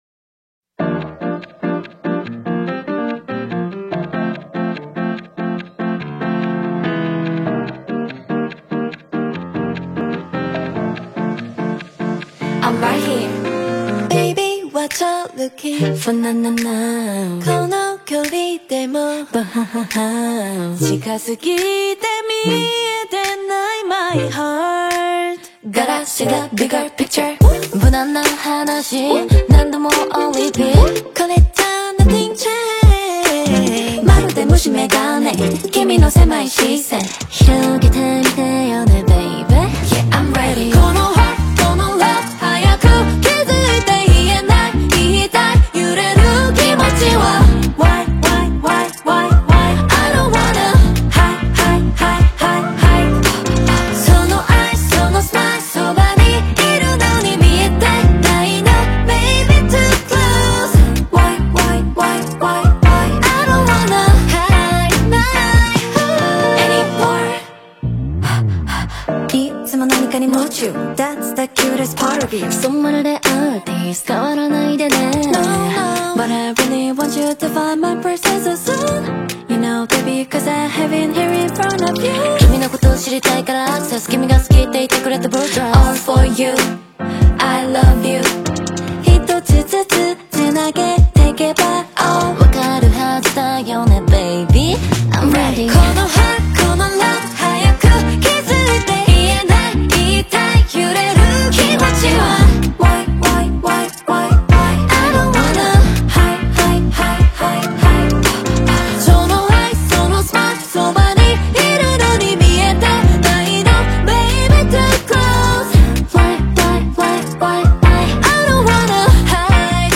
Азиатские хиты